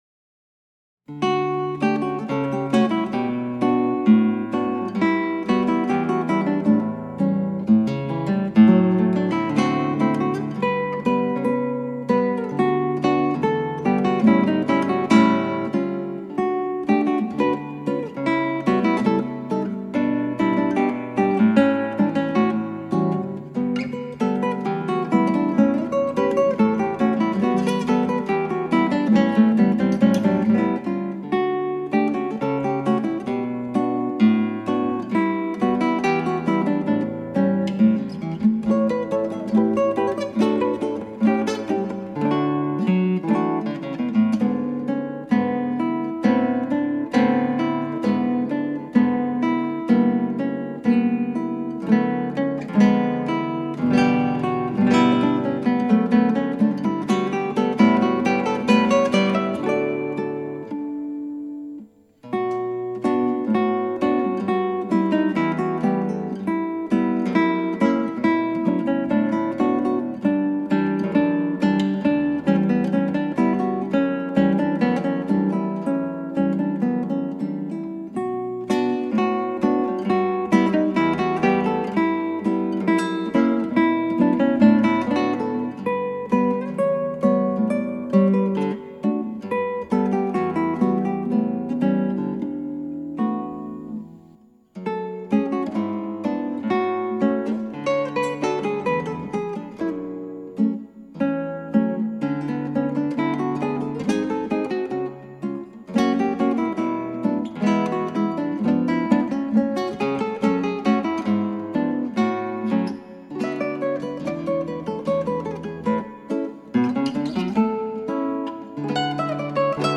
クラシックギター　ストリーミング　コンサート
二拍子っぽいリズムで、ちょっと前のめり？かな。
表現がオーバーなところが気になります。